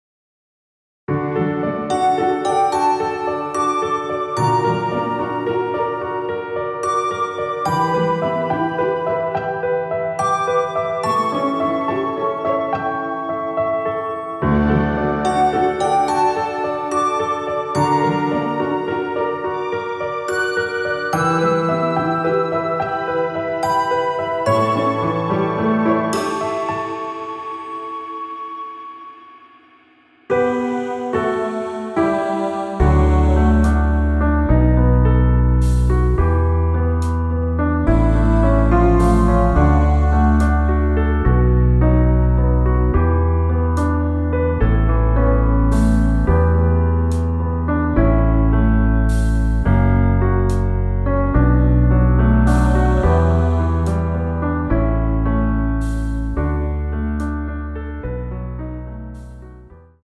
엔딩이 페이드 아웃이라 노래하기 편하게 엔딩을 만들어 놓았습니다.(멜로디 MR 미리듣기 확인)
원키에서(-2)내린 MR입니다.
앞부분30초, 뒷부분30초씩 편집해서 올려 드리고 있습니다.
중간에 음이 끈어지고 다시 나오는 이유는